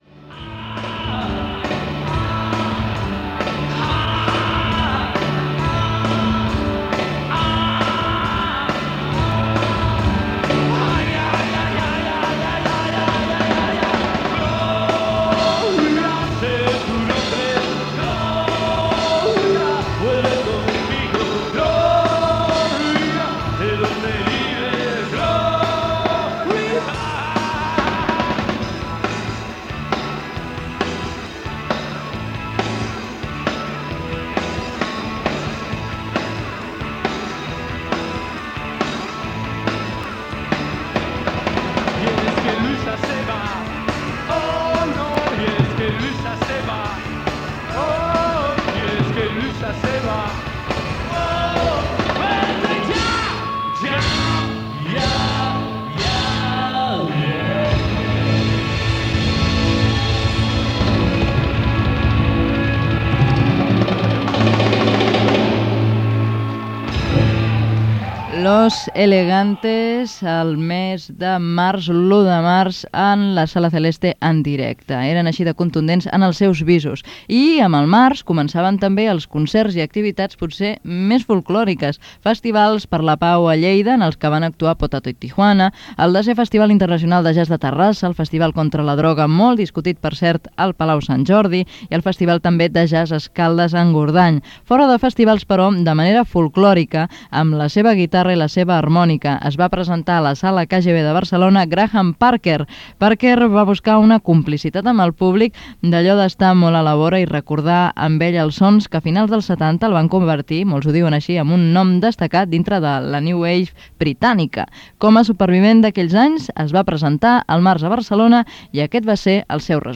Tema musical, comentari del tema que s'ha escoltat, cronologia d'alguns festivals, comentaris i temes musicals